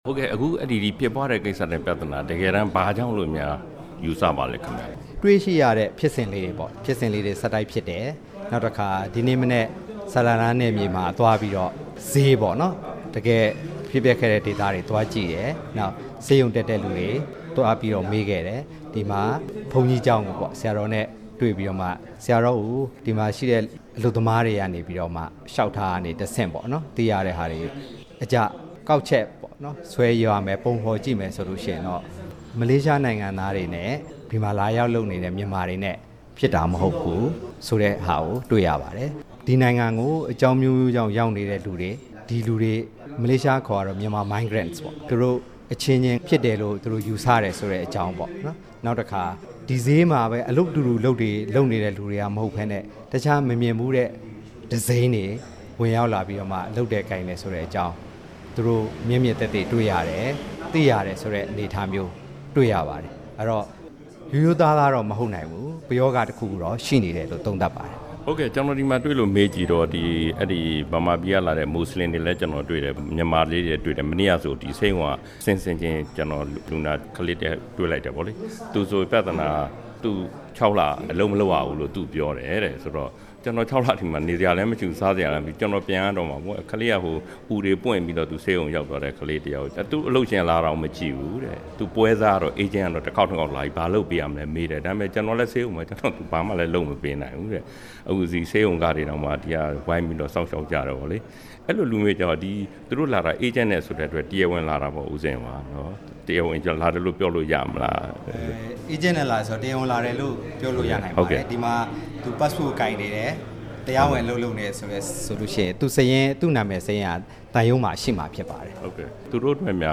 မလေးရှားကို　ရောက်ရှိနေတဲ့ ဒုတိယနိုင်ငံခြားရေးဝန်ကြီး ဦးဇင်ယော်နဲ့ RFA　တွေ့ဆုံမေးမြန်းချက်
မေးမြန်းခန်း